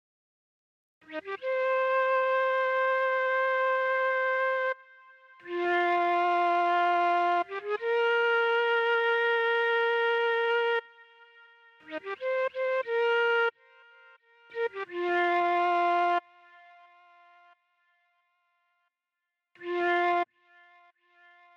AV_Flute_Melody_100bpm_Fmin